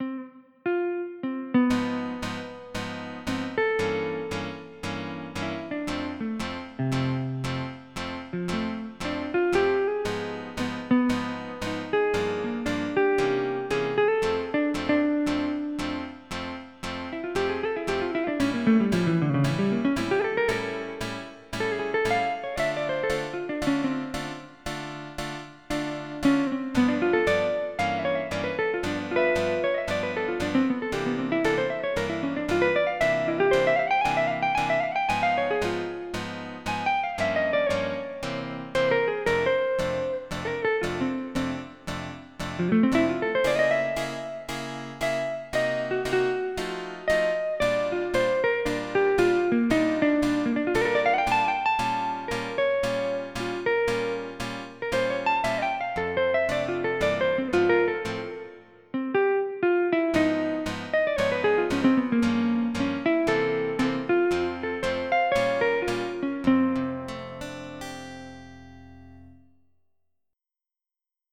Jazz
MIDI Music File
Type General MIDI